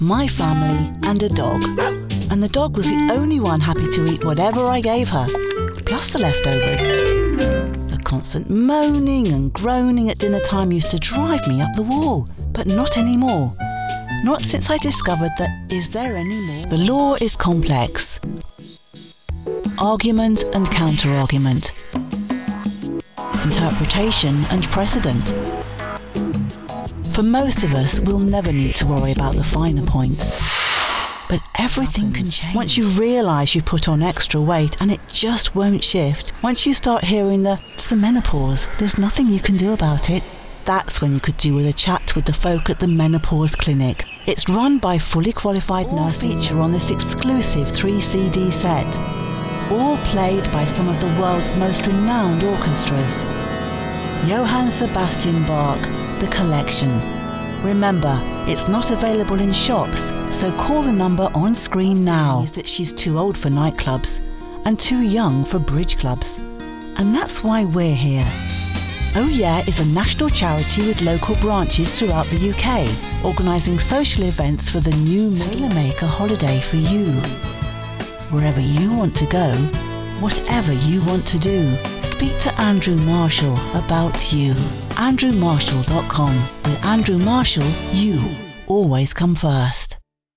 An English voice artist with a warm & reassuring voice
Commercial Reel [#6 spots 90 seconds]
Received Pronounciation
With a broadcast quality professional home recording space, I have voiced corporate videos, TV, radio and on-line/social media commercials, character roles, telephone/IVR and explainers.
My voice age range is 40’s – 60’s and accent English RP.